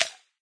plasticice2.ogg